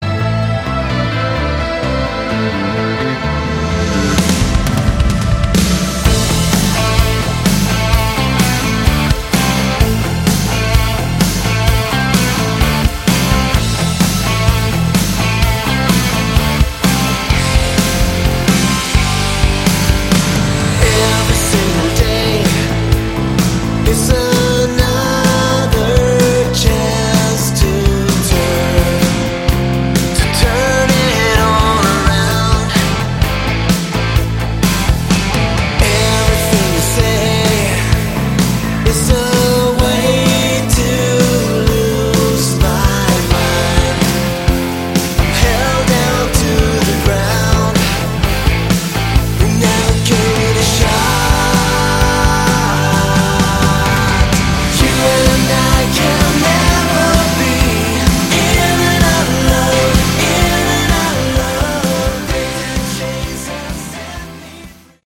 Category: AOR / Melodic Rock
guitar, backing vocals
vocals, keyboards